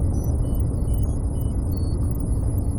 turret pod.ogg